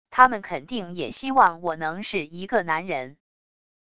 他们肯定也希望我能是一个男人wav音频生成系统WAV Audio Player